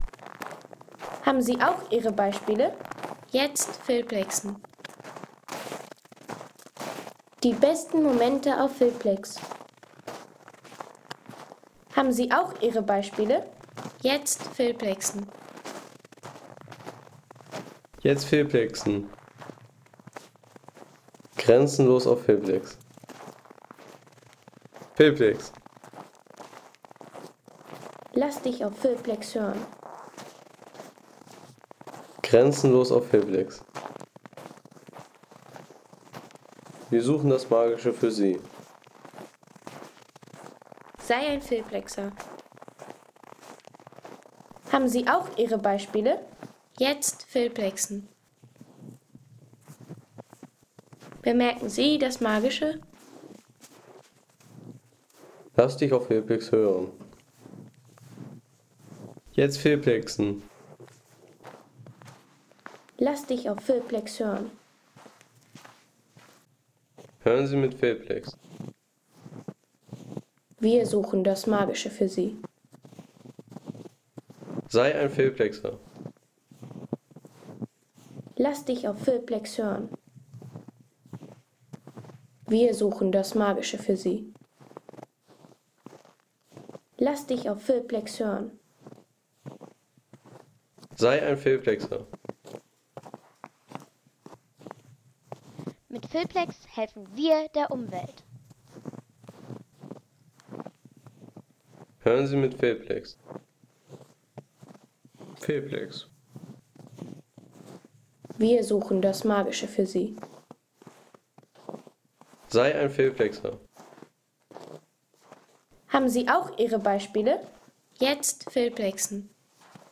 Schneeknirschgeräusche
Schneeknirschgeräusche Home Sounds Natur Schnee Schneeknirschgeräusche Seien Sie der Erste, der dieses Produkt bewertet Artikelnummer: 94 Kategorien: Natur - Schnee Schneeknirschgeräusche Lade Sound.... Schneeknirschgeräusche in Flensburg bei -6 °C. Am 1.